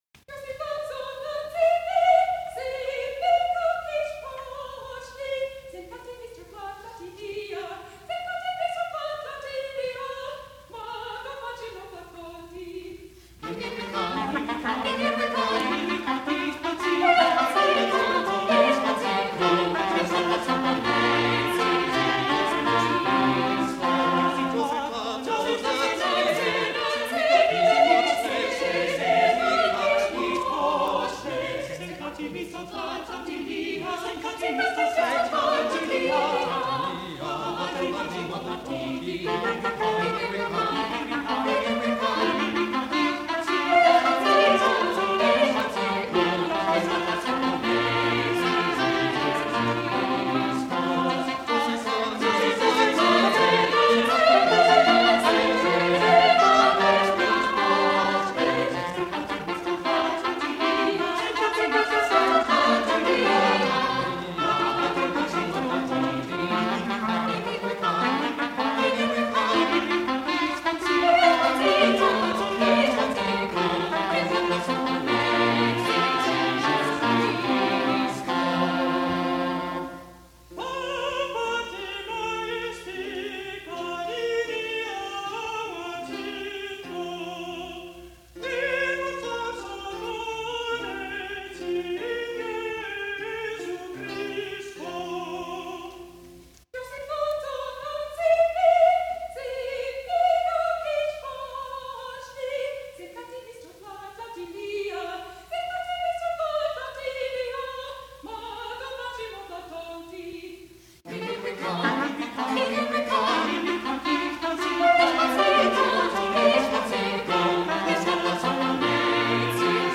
The villancico verse//refrain form invited a treatment similar to that found in the spiritual villancicos of Guerrero and Fernandes.
soprano
countertenor